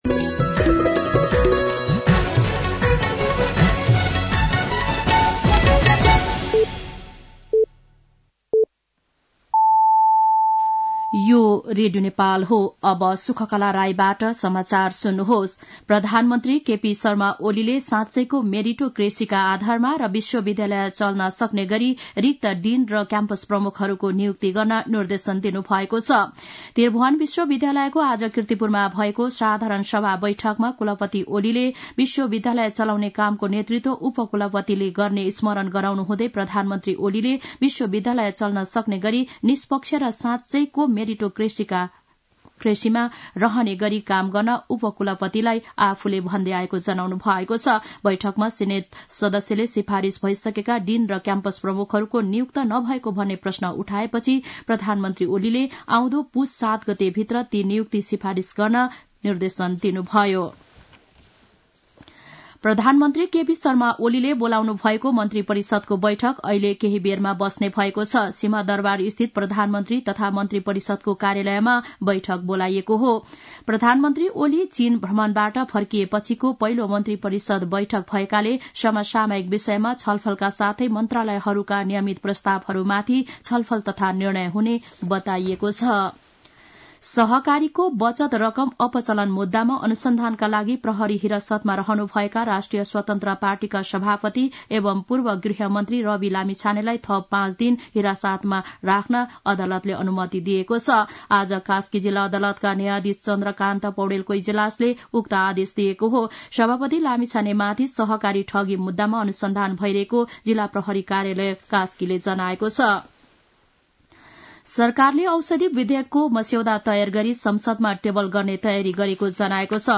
साँझ ५ बजेको नेपाली समाचार : २८ मंसिर , २०८१
5-PM-NEWS.mp3